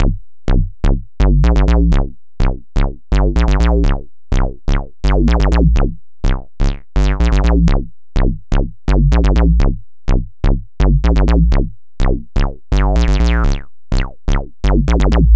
cch_acid_grit_125.wav